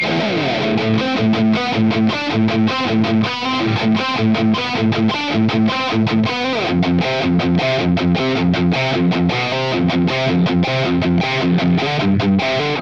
The JCM900 pack includes captures ranging from clean tones to heavily distorted and everything in between plus my personal YouTube IR that I use in my demos are also included.
Metal Riff
RAW AUDIO CLIPS ONLY, NO POST-PROCESSING EFFECTS